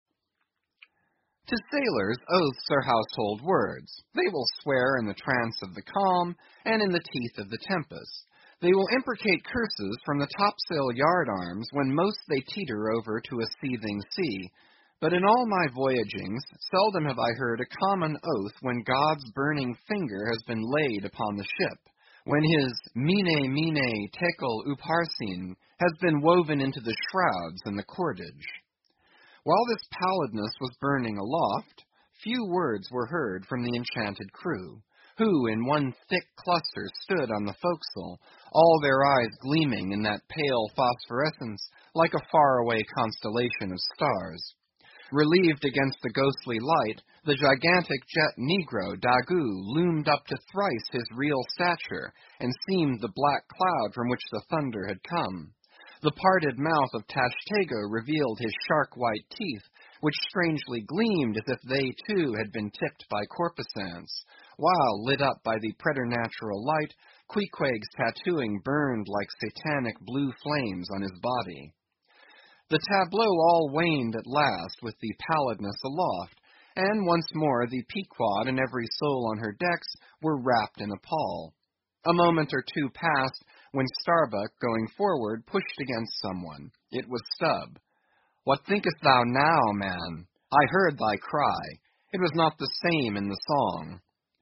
英语听书《白鲸记》第942期 听力文件下载—在线英语听力室